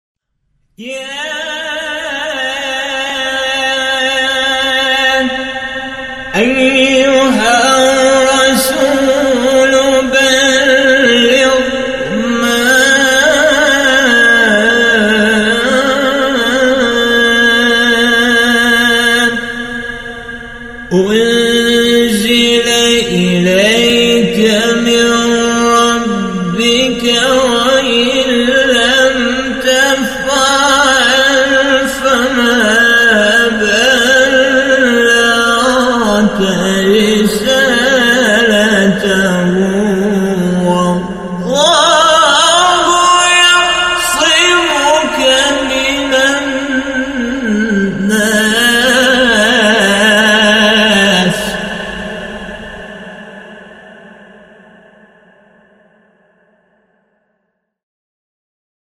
مقام سه گاه * رست استاد طنطاوی | نغمات قرآن | دانلود تلاوت قرآن